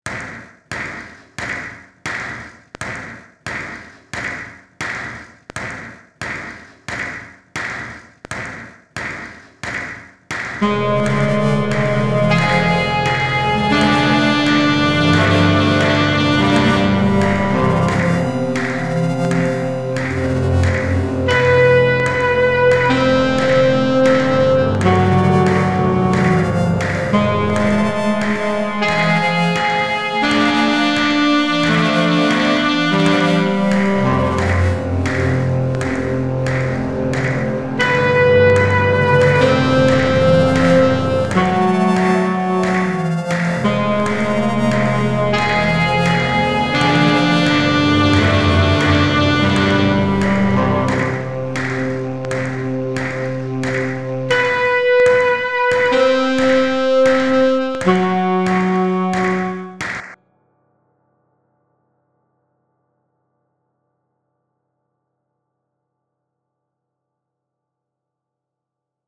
Alcuni degli audio prodotti nel laboratorio di registrazione sonora: Registriamo il nostro ambiente